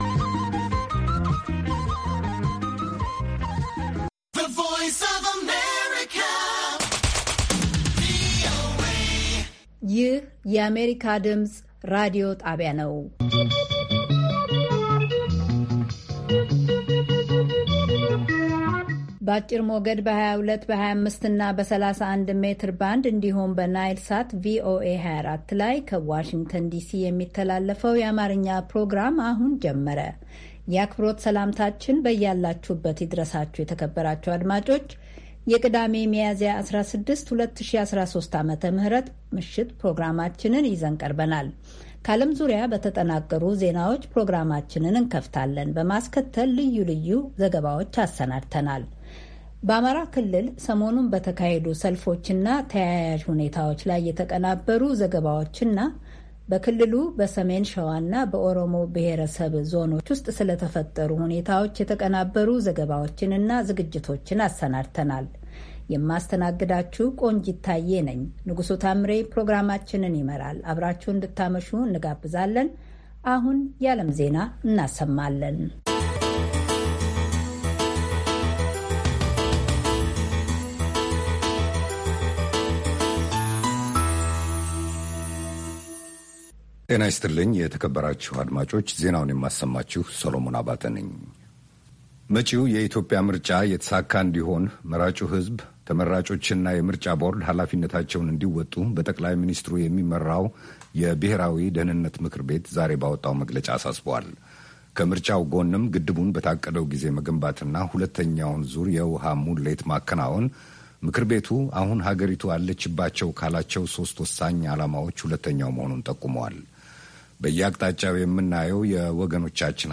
ቅዳሜ፡-ከምሽቱ ሦስት ሰዓት የአማርኛ ዜና